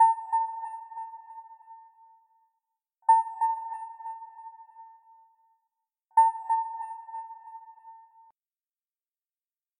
На этой странице собраны звуки сонаров подводных лодок — от монотонных импульсов до сложных эхолокационных сигналов.
Звук эхолокации в киноиндустрии